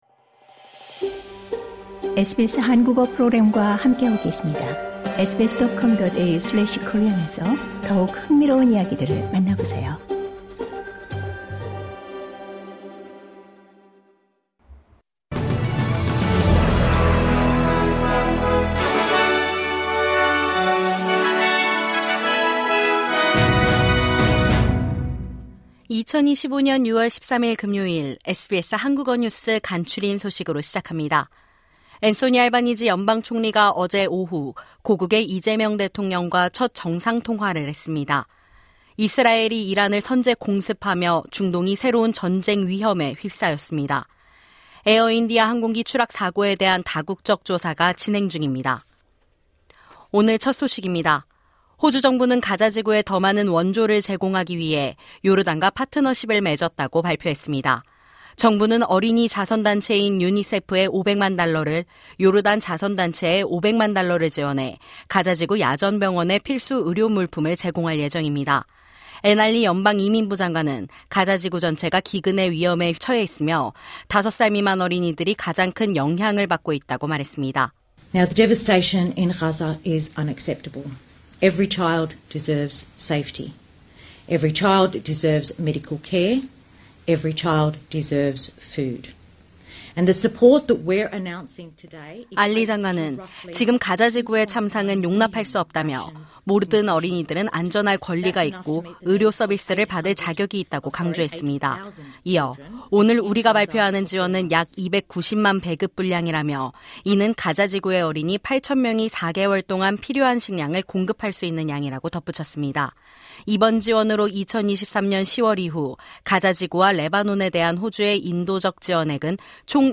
매일 15분 내로 정리하는 호주 뉴스: 6월 13일 금요일